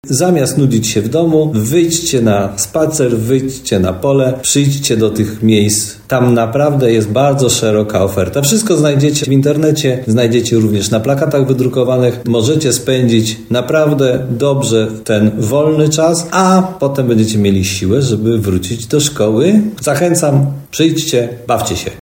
Mówi prezydent Tarnobrzega Dariusz Bożek.